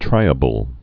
(trīə-bəl)